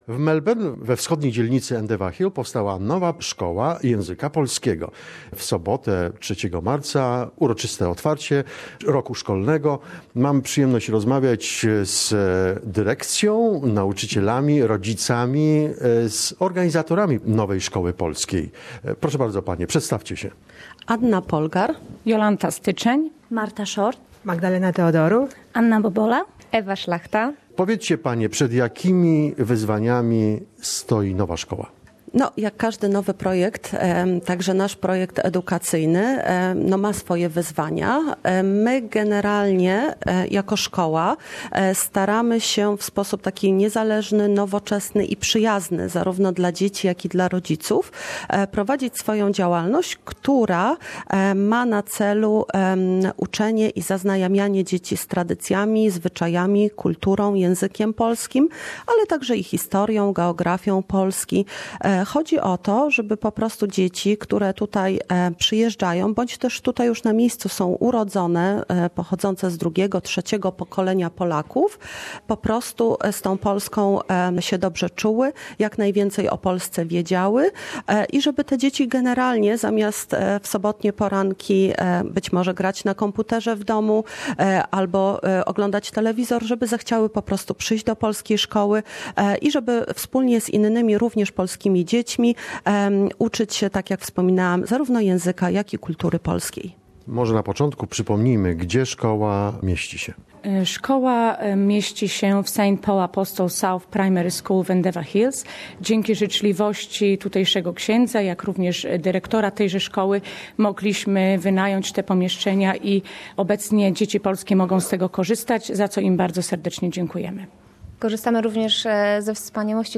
The school year began...Teachers, parents and children talk about the new Polish language school located in Endeavour Hills.